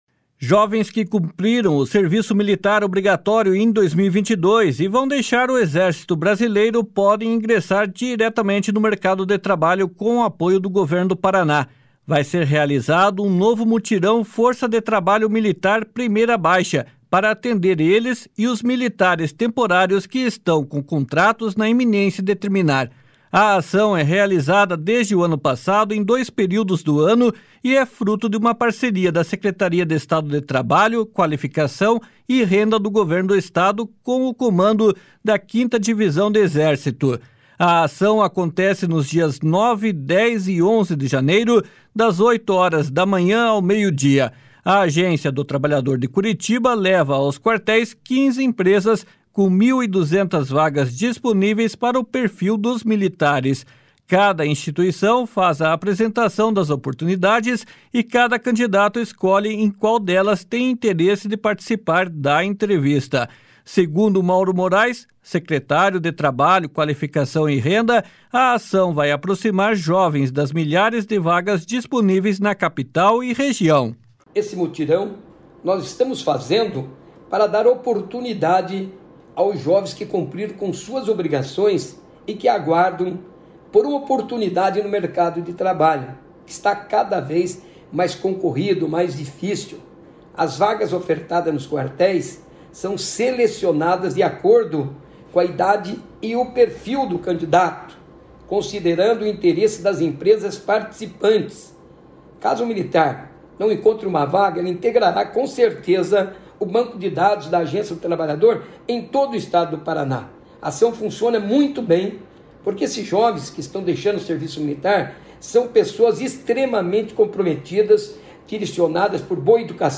Segundo Mauro Moraes, secretário de Trabalho, Qualificação e Renda, a ação vai aproximar jovens das milhares de vagas disponíveis na Capital e Região. //SONORA MAURO MOARES//